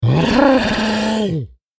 クリーチャーボイス11.mp3